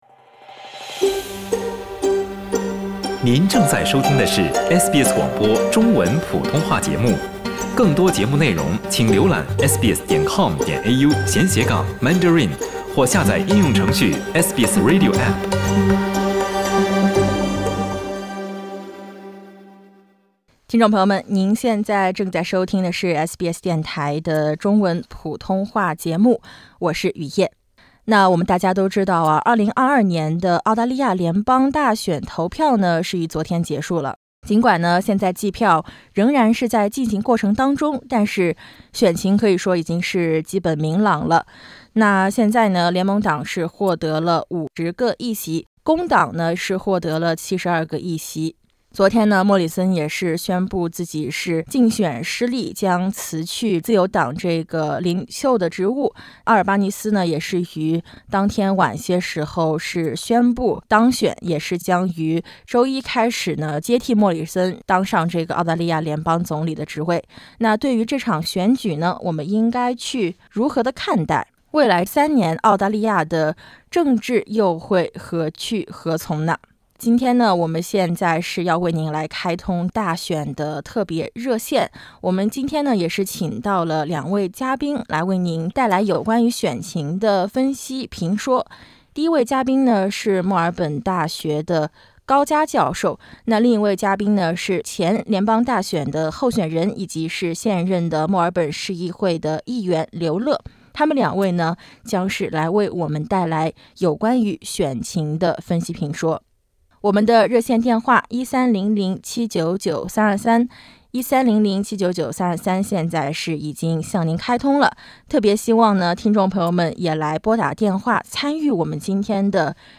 听众朋友们也在热线节目中各抒已见，点击封面音频，收听完整节目。